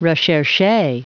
Prononciation du mot recherche en anglais (fichier audio)
Prononciation du mot : recherche